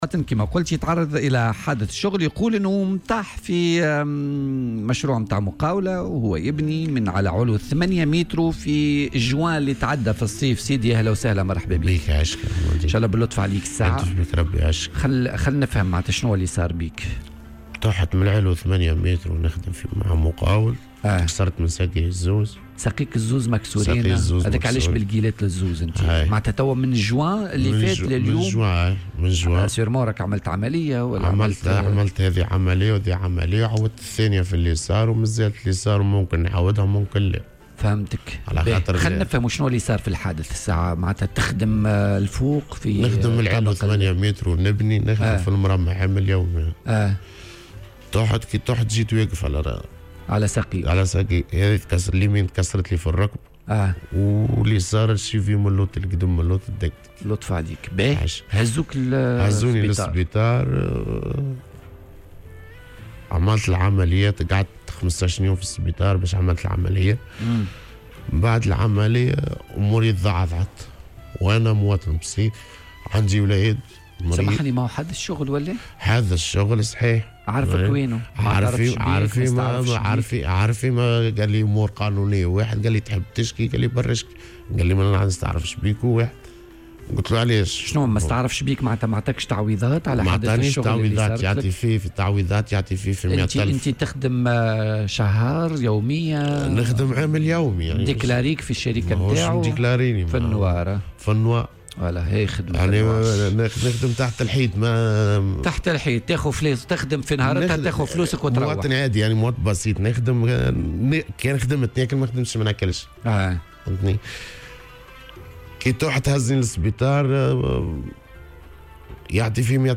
أكد عامل بناء تعرضه إلى حادث شغل خلال شهر جوان الماضي، وذلك بعد سقوطه من بناية (من عُلو 8 أمتار)، مما استوجب خضوعه لتدخّل جراحي على مستوى ساقيه.